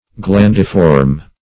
Search Result for " glandiform" : The Collaborative International Dictionary of English v.0.48: Glandiform \Gland"i*form\, a. [L. glans, glandis, acorn + -form: cf. F. glandiforme .]